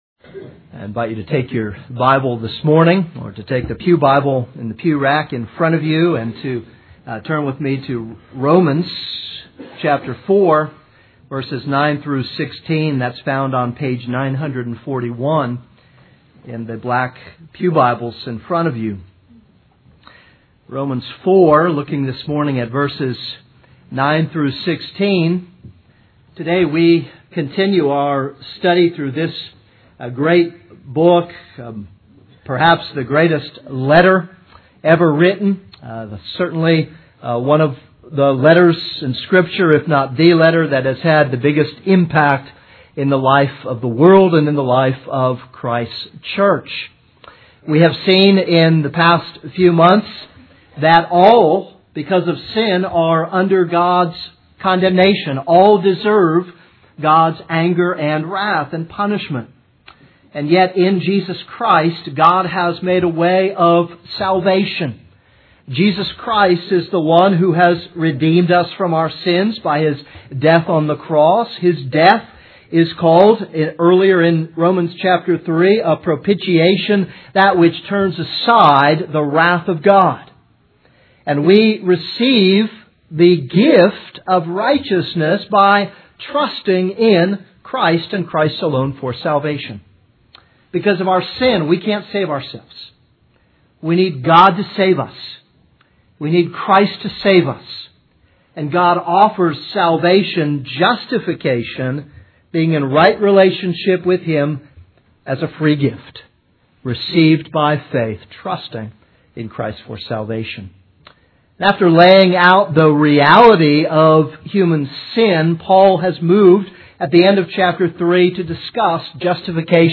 This is a sermon on Romans 4:9-16.